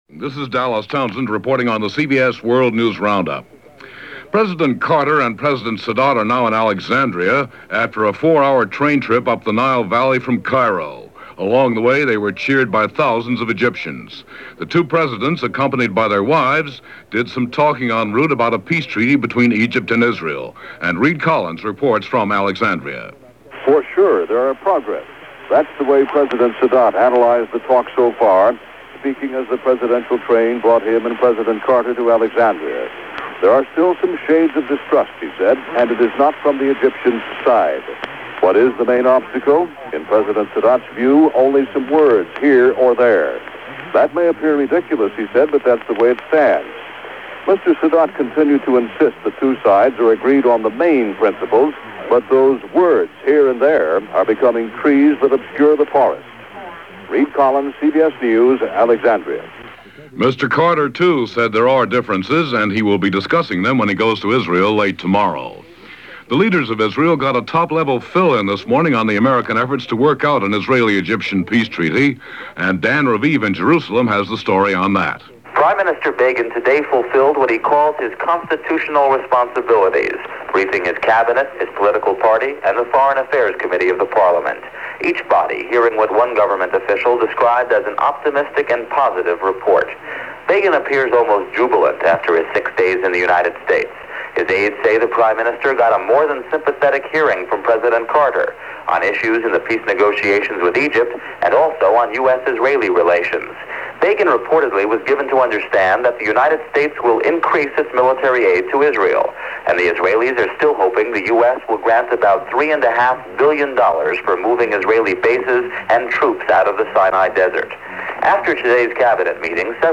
And that’s a small portion of what went on for this March 9th in 1979, as presented by The CBS World News Roundup and CBS Radio Hourly News.